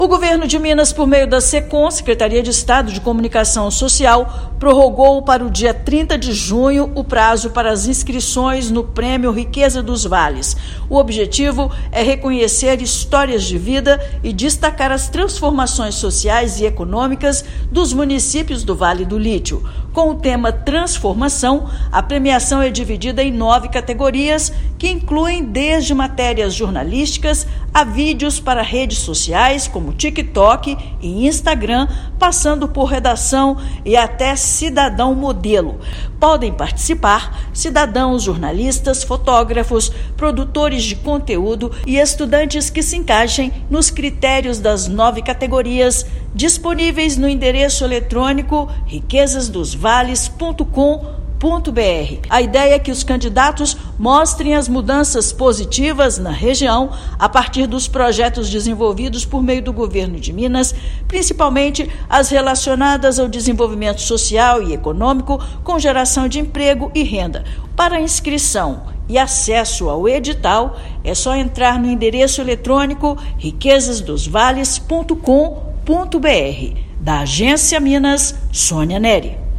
Agora, interessados têm até o dia 30/6 para garantir a participação; ao todo, serão R$ 450 mil em prêmios. Ouça matéria de rádio.